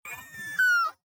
nutria-v3.ogg